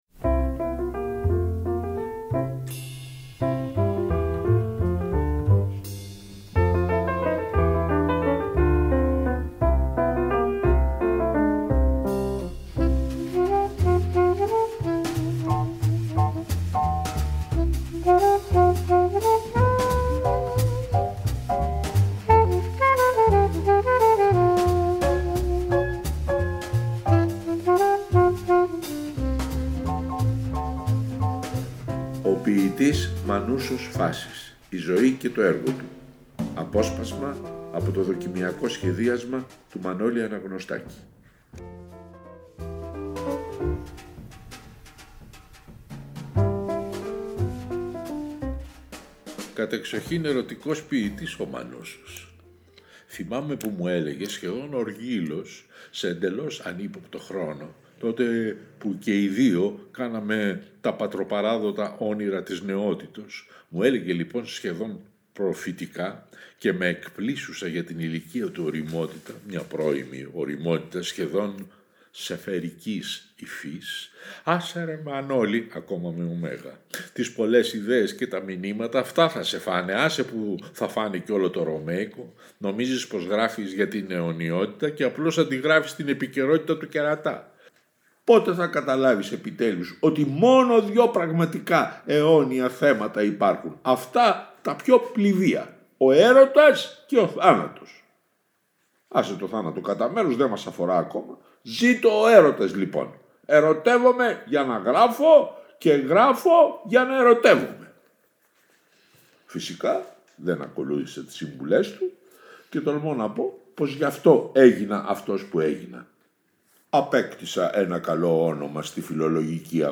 * το μουσικό κομμάτι που ακούγεται είναι το “Three to get ready” του Dave Brubeck